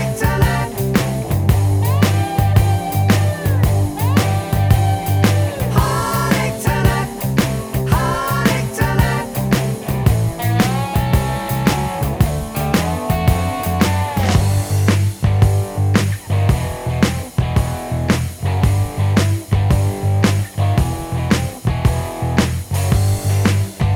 No Guitar Soft Rock 4:26 Buy £1.50